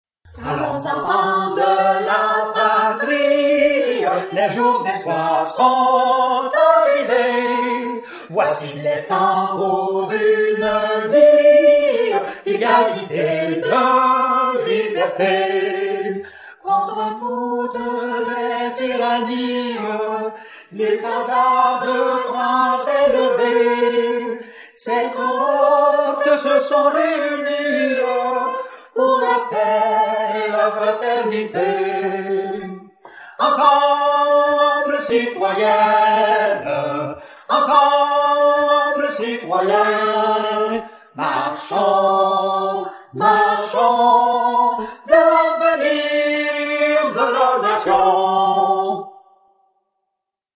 La Citoyenne, Solo, Human Voice